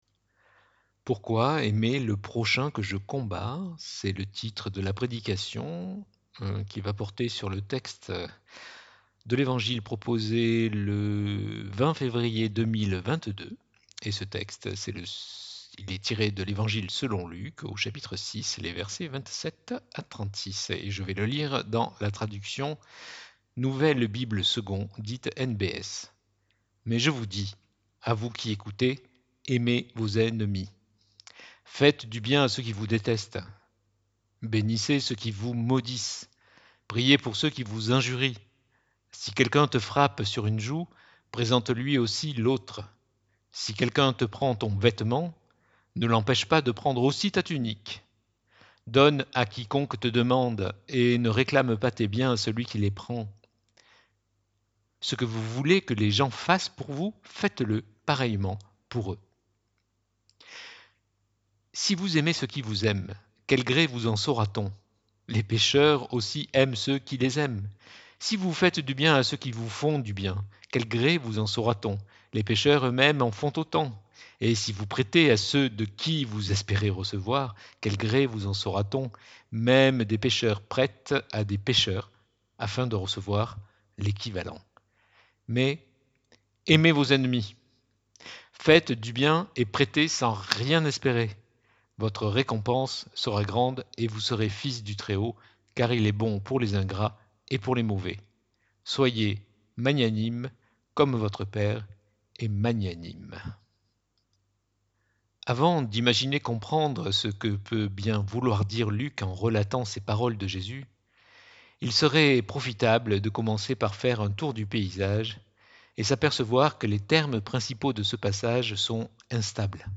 Prédication du 20 février 2022.mp3 (31.75 Mo) Prédication 20 février 2022.pdf (294.81 Ko)